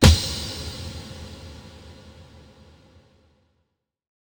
Big Drum Hit 30.wav